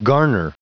Prononciation du mot garner en anglais (fichier audio)
Prononciation du mot : garner